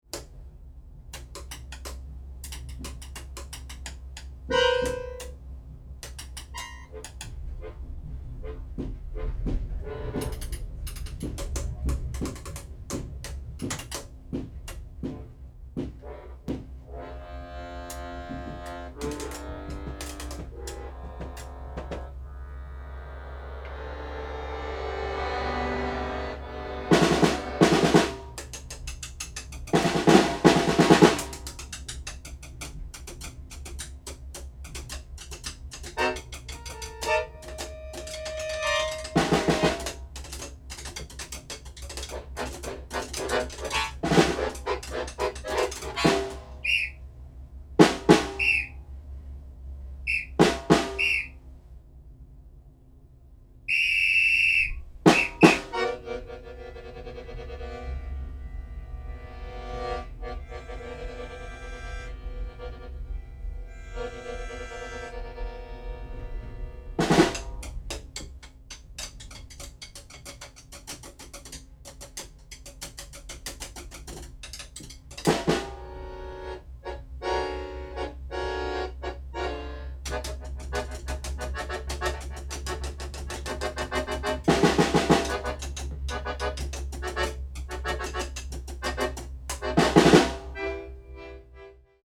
Anmerkung: Uraufführung fand in einer fahrenden Straßenbahn statt.
für Akkordeon, Triolas und Schlagzeug